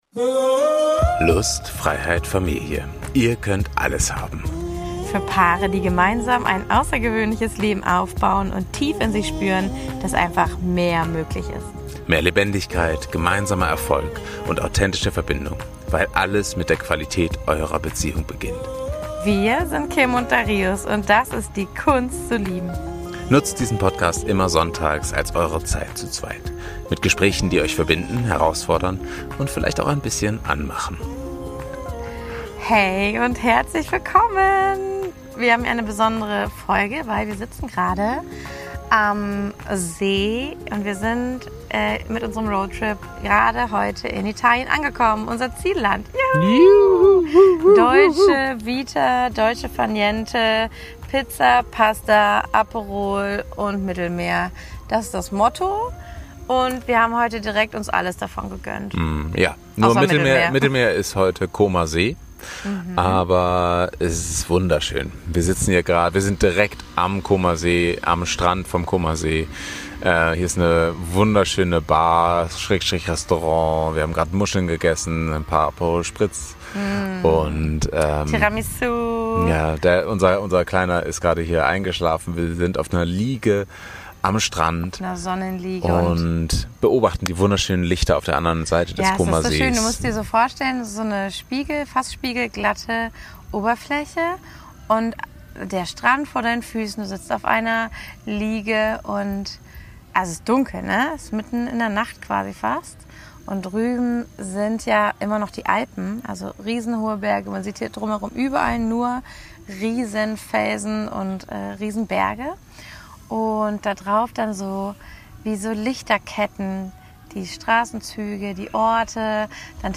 #14 Von Aperol, Ohrwürmern & echten Wins – eine Sommerfolge vom Comer See
Manchmal sind es nicht die Deep Dives, die verbinden – sondern das gemeinsame Lachen, Erzählen und Innehalten. In dieser Folge nehmen wir euch mit an den Comer See, mitten in unser Dolce Vita zwischen Aperol Spritz, Kinderschlaf am Strand und lauen Sommernächten.